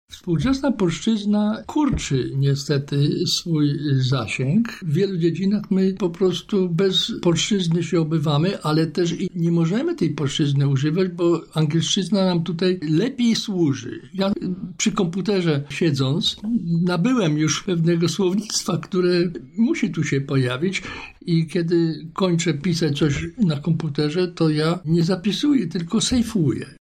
mówi językoznawca